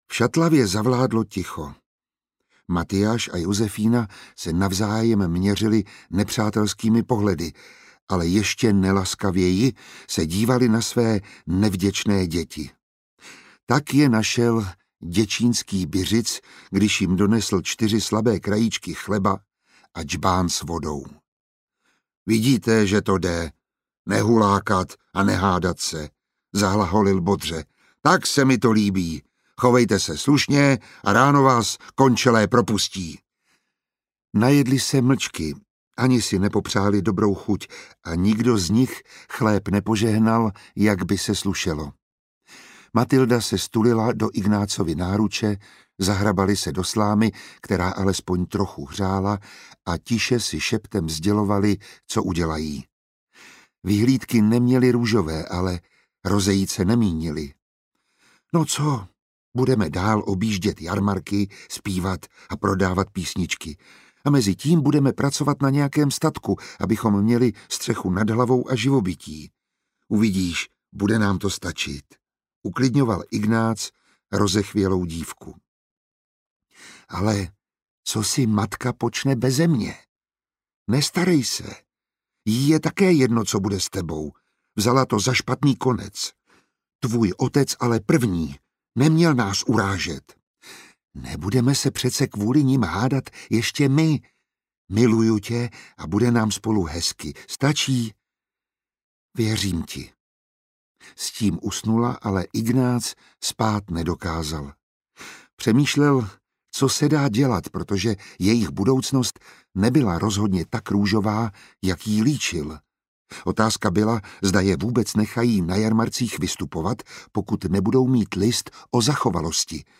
Křišťálový klíč IV. audiokniha
Ukázka z knihy
• InterpretMiroslav Táborský, Saša Rašilov, Dana Černá